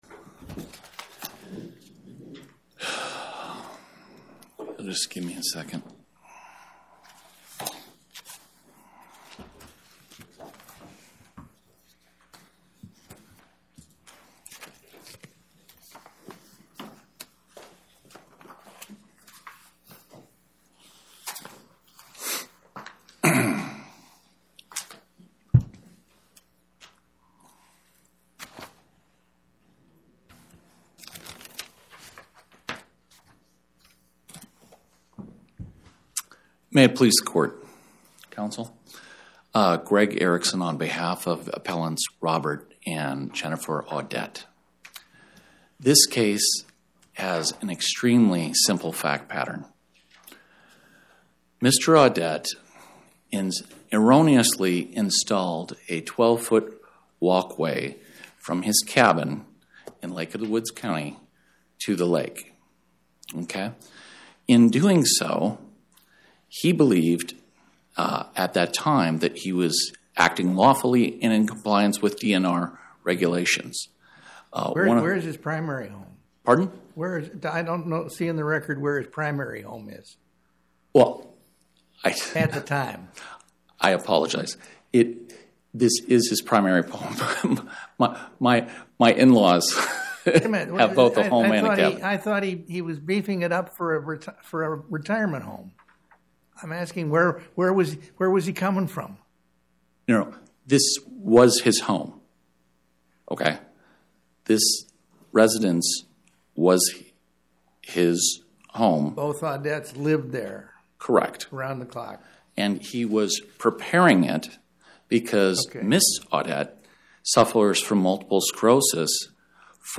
Oral argument argued before the Eighth Circuit U.S. Court of Appeals on or about 02/10/2026